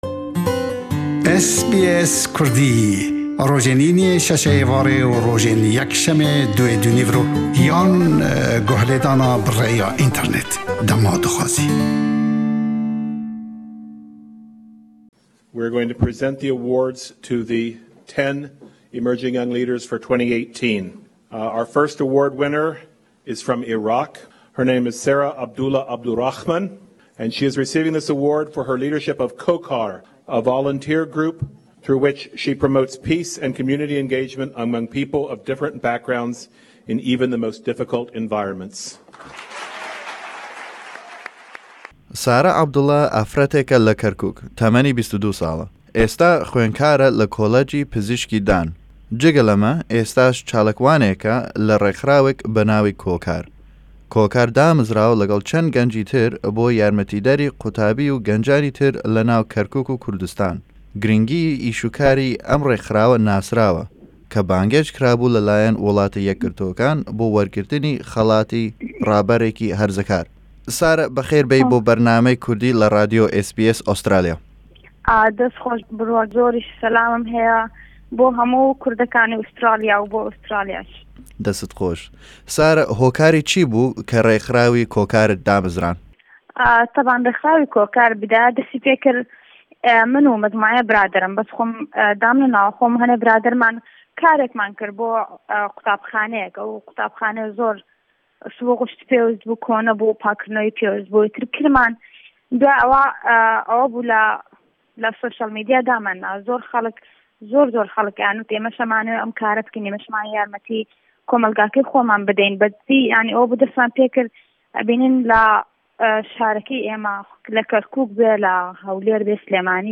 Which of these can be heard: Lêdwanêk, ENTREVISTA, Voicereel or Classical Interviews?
Lêdwanêk